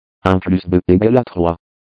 Französische Stimmen
Lernout & Hauspie® TTS3000 TTS engine – French